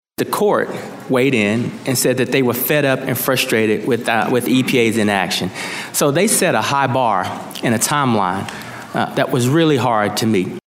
Environmental Protection Agency chief Michael Regan was called out on biofuel use, pesticide registrations, and the Biden Waters of the U.S. rule at a House Ag hearing.